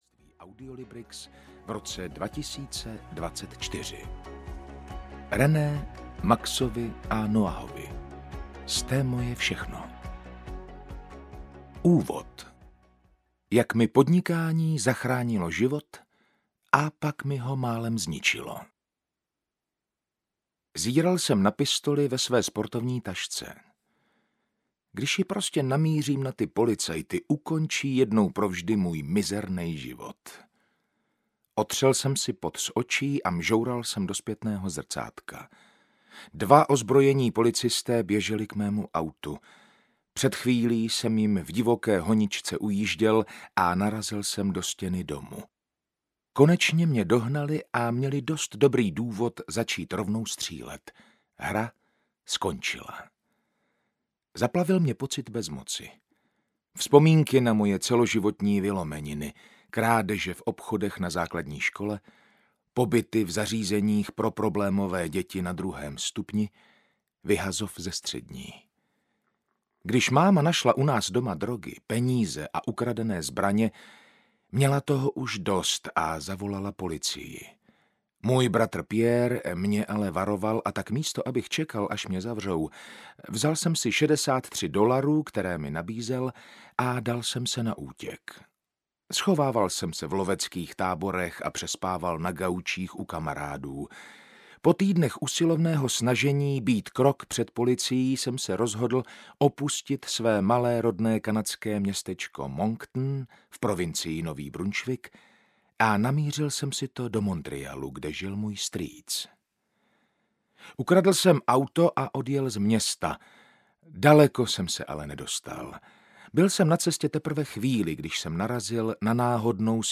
Kupte si zpátky svůj čas audiokniha
Ukázka z knihy